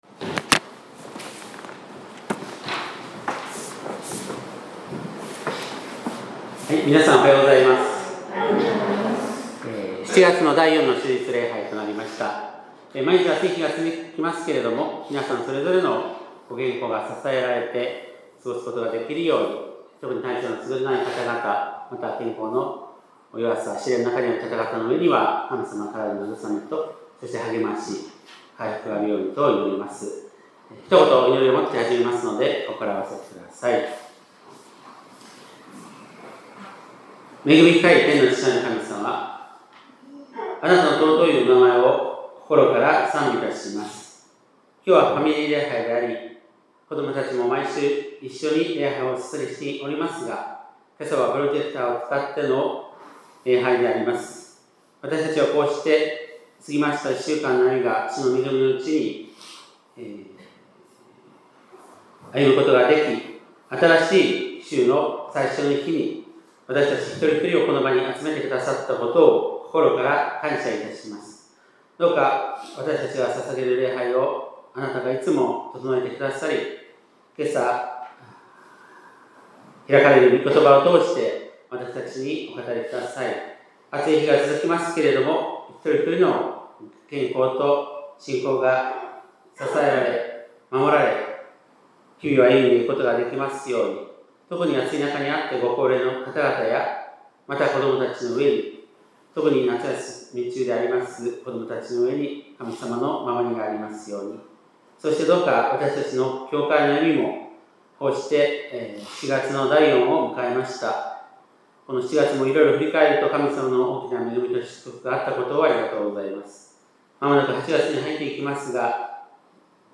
2025年7月27日（日）礼拝メッセージ